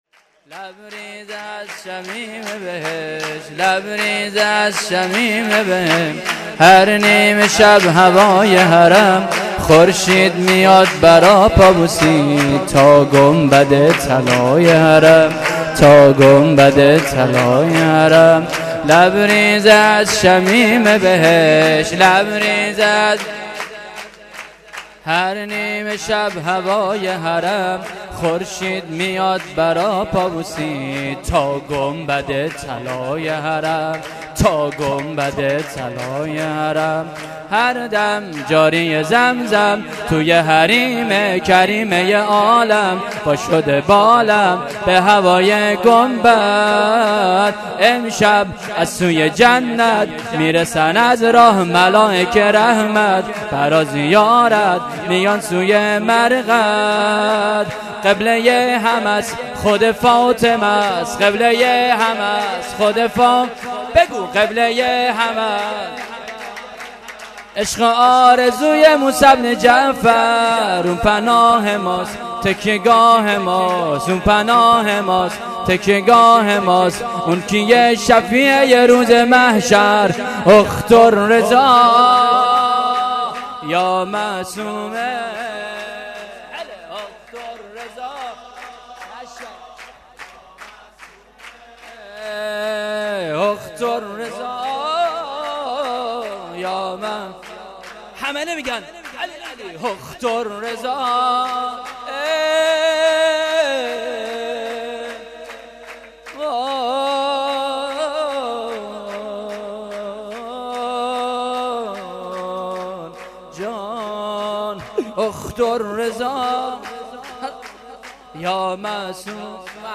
🔸ولادت حضرت فاطمه معصومه(ع)۹۸🔸